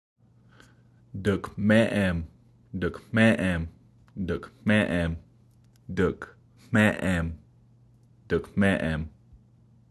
dukMéʔem wáta (dook-MEH-em wah-tah), which means “Wave Creek” beginning this summer.
DukMeEm pronunciation.m4a